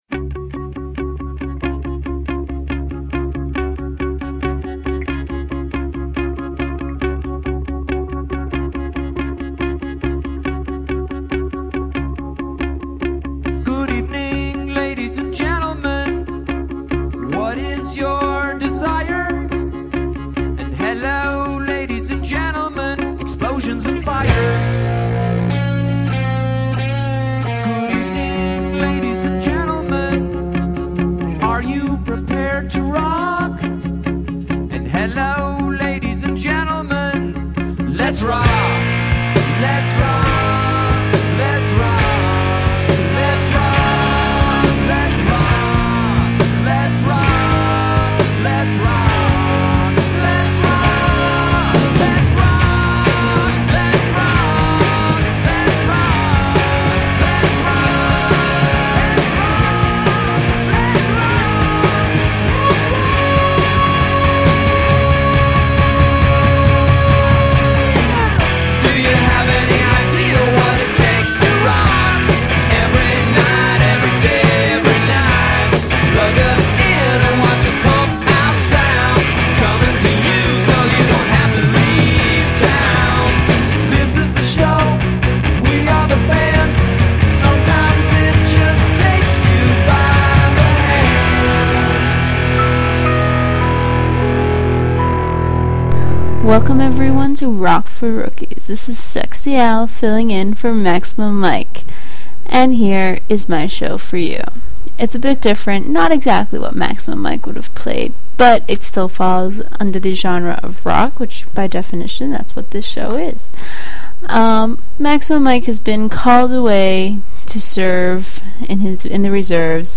Songs to get you up and dancing. Feel free to sing along!